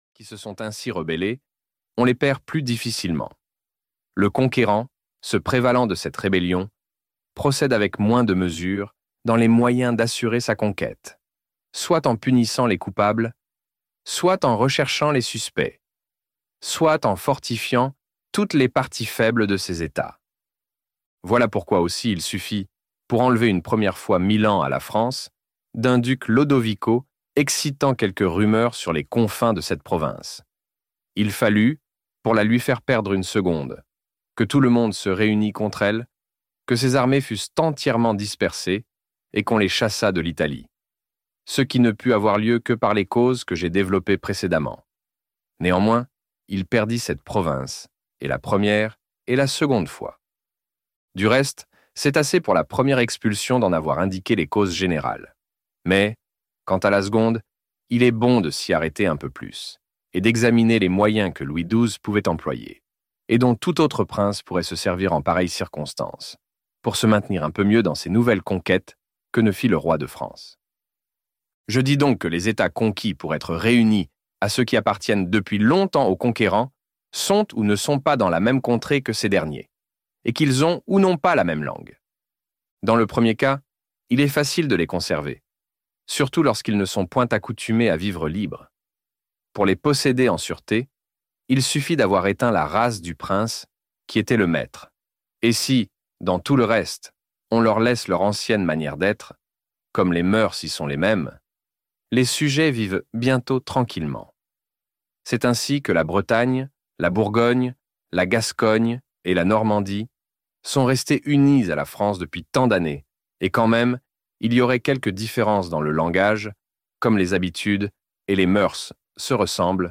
Le Prince - Livre Audio